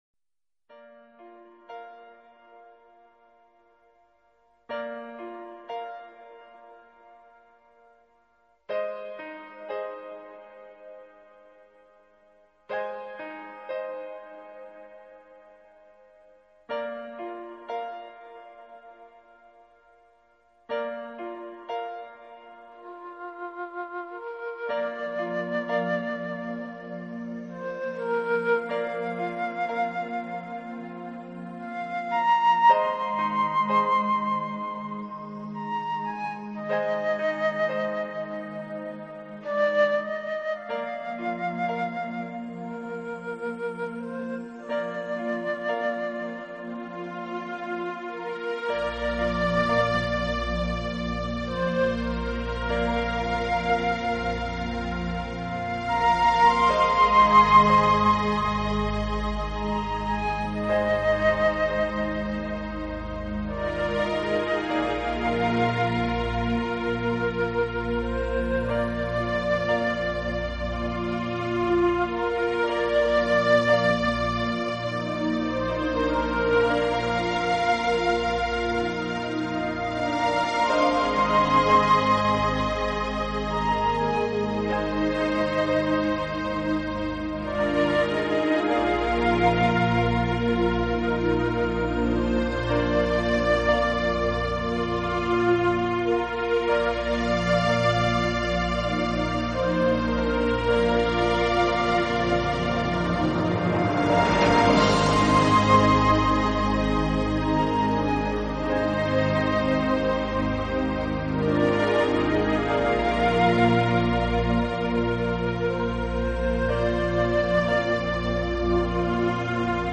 Genre..........: New Age
它创造了一个宁静而富有灵感
的音乐世界，伴随着澳大利亚本土的鸟声，实在是冥想我放松的绝佳音乐……